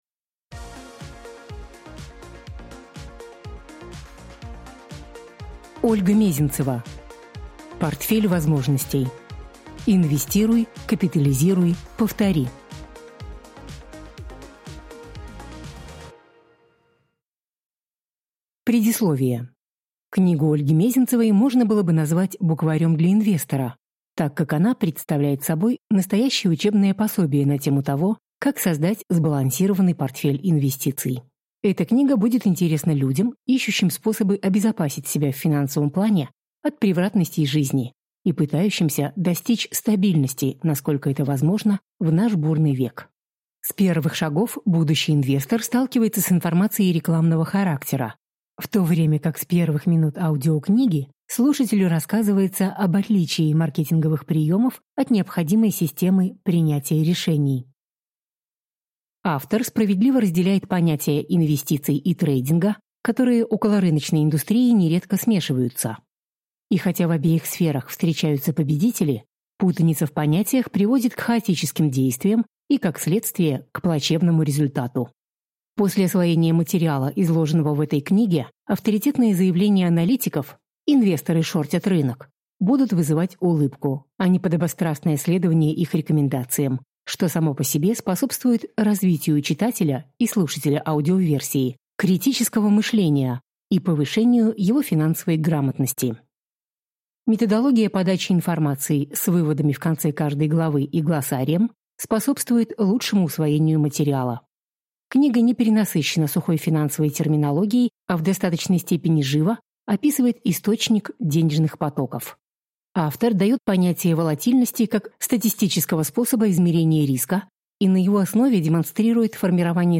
Аудиокнига Портфель возможностей. Инвестируй, капитализируй, повтори | Библиотека аудиокниг